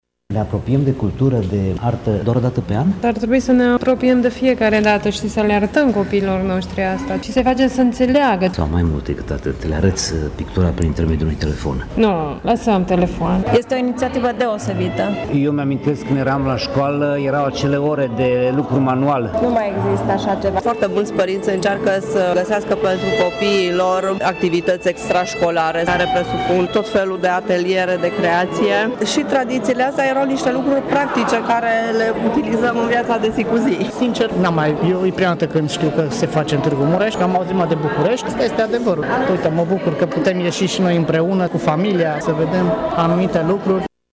Oamenii în general și tinerii în mod special trebuie să aibă contact direct cu obiectul, lucru subliniat și cu cetățenii ce vizitau punctele de interes ale Nopții Muzeelor de la Tg. Mureș.